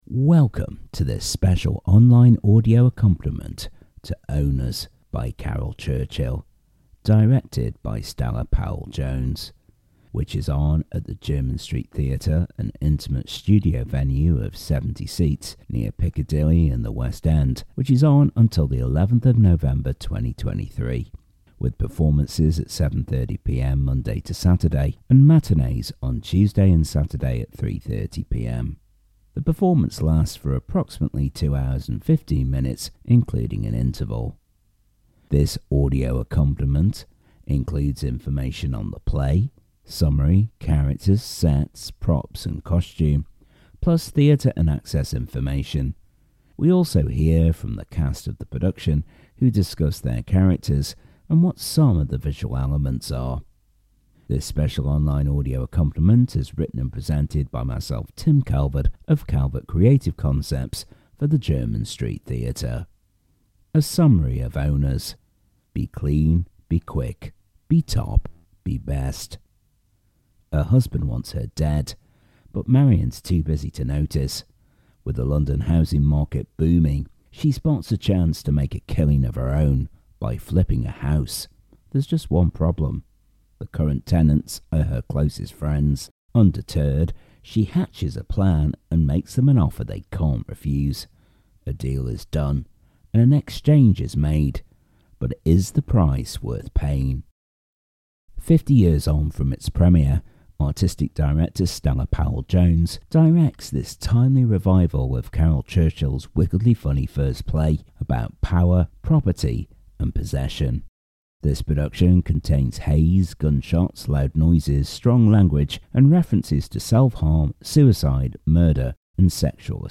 This audio accompaniment includes information on the play, summary, characters, Set, props and costume, plus theatre and access information, we also hear from the cast of the production who discuss their characters and what some of the visual elements are.